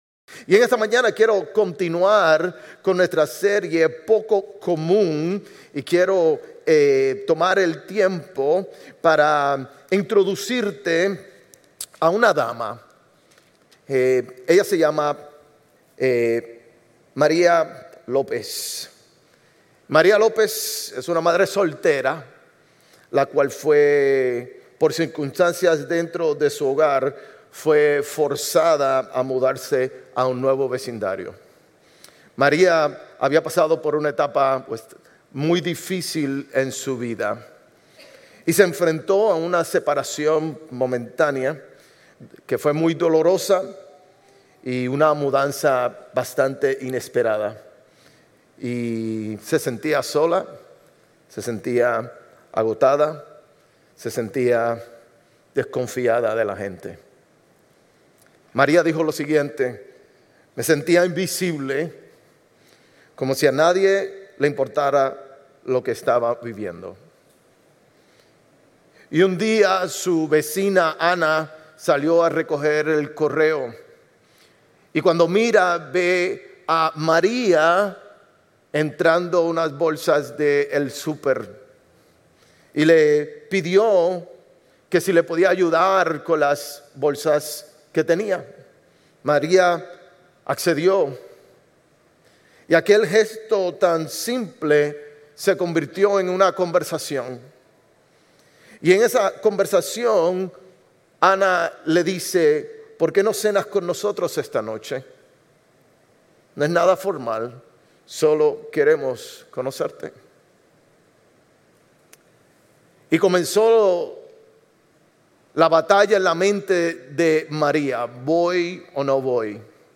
Sermones Grace Español 8_3 Grace Espanol Campus Aug 04 2025 | 00:42:13 1x 00:00 / 00:42:13 Subscribe Share RSS Feed Share Link Embed